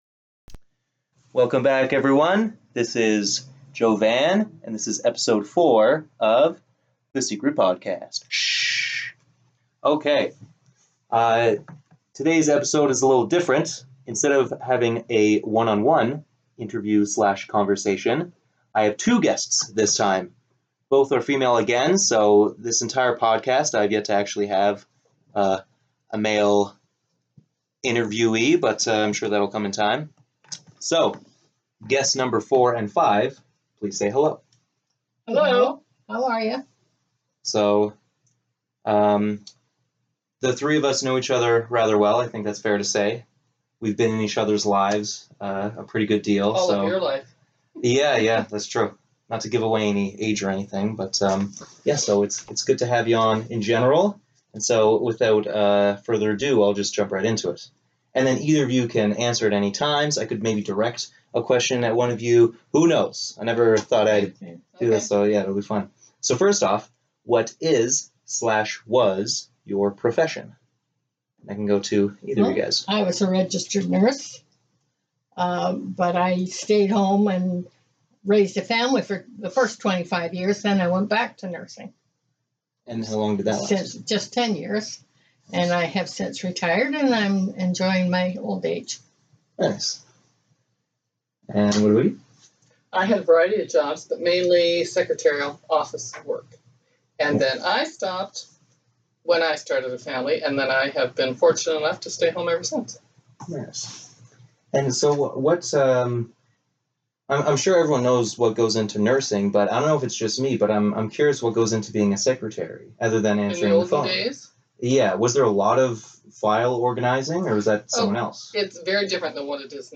In this episode I interview two guests at once.